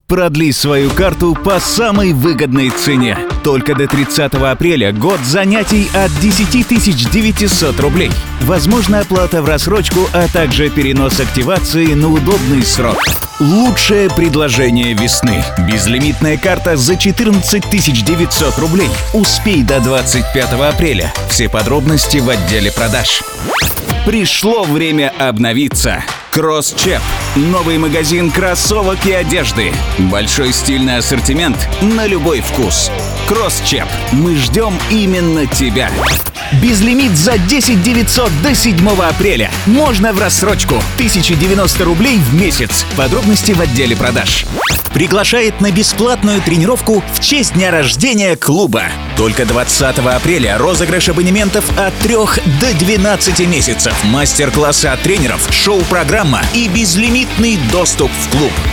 У микрофона с 2007 года Позитивный, искренний голос.
Тракт: Condenser microphone - cust. mic m7 caps. Channel Strip warmer sound m40& drawmer dl241